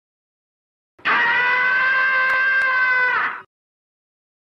Tom-Screaming-Sound-Effect-From-Tom-and-Jerry.mp3